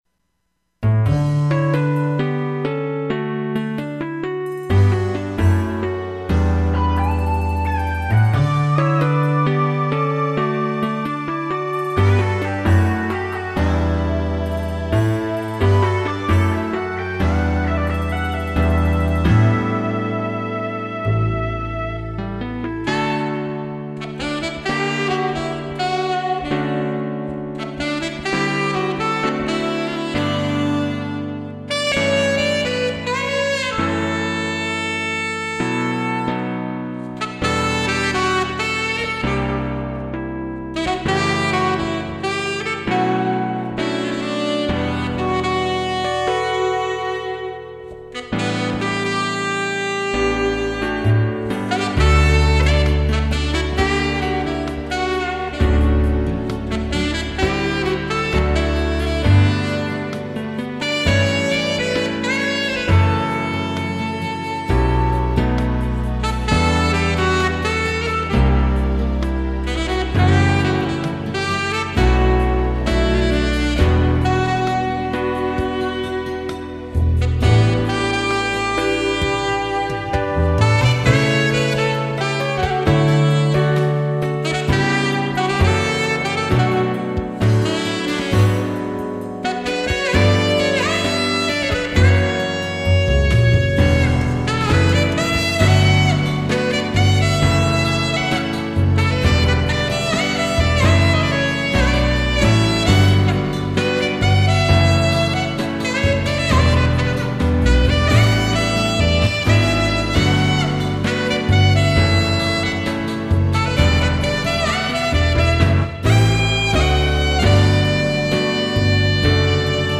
2006-02-06 오후 8:15:00 감미로운 섹소폰 연주입니다.